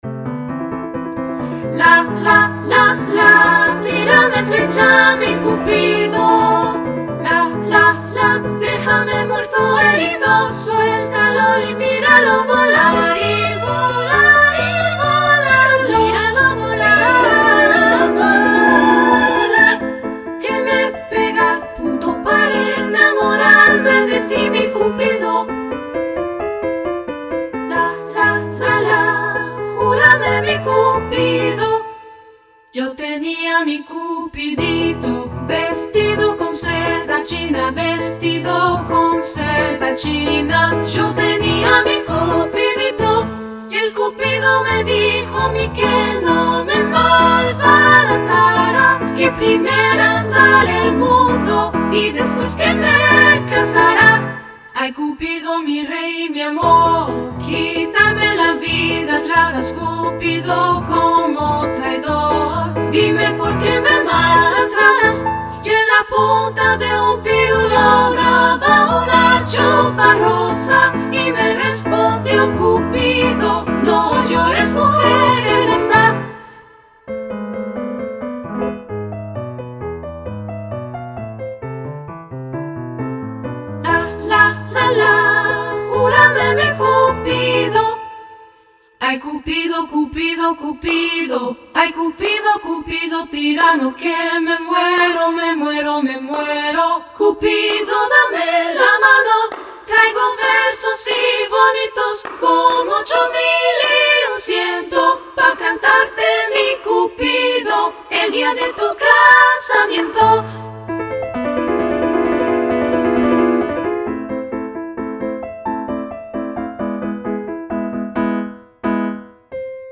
SA + Piano 3’30”
SA, Piano